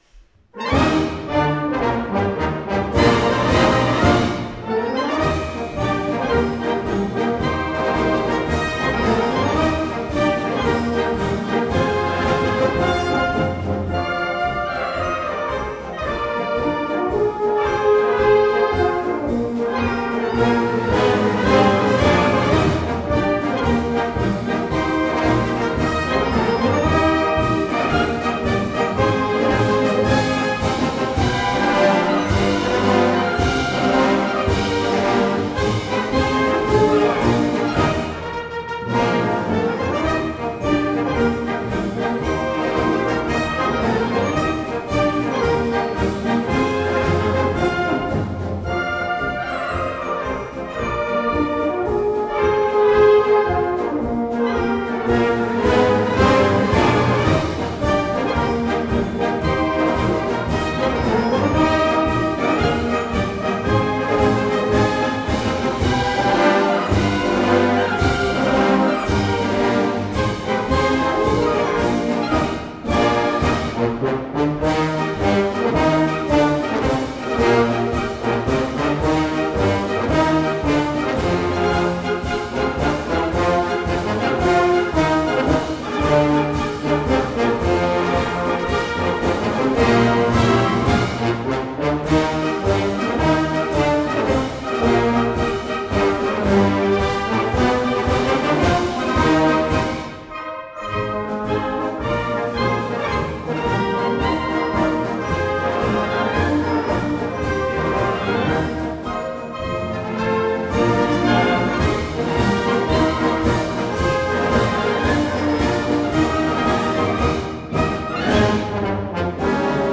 Gattung: Marsch für Blasorchester
Besetzung: Blasorchester